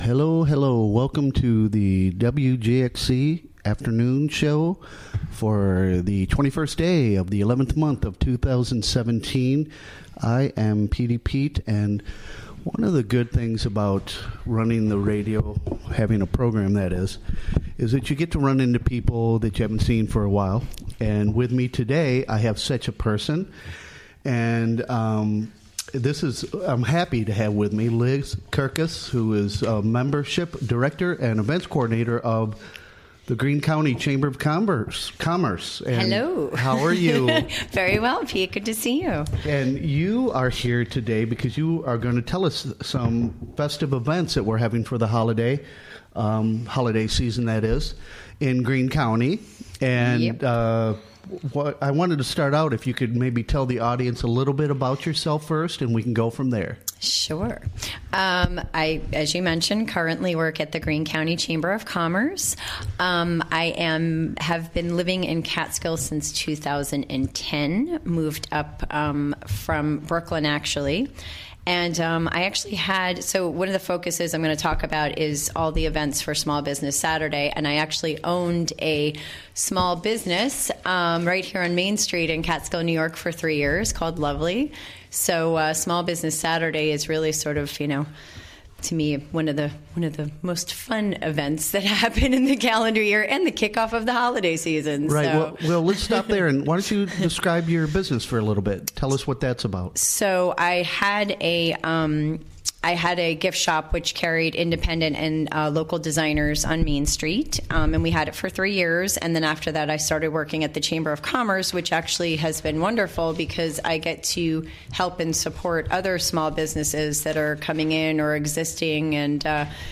Recorded during the WGXC Afternoon Show of Tuesday, Nov. 21, 2017.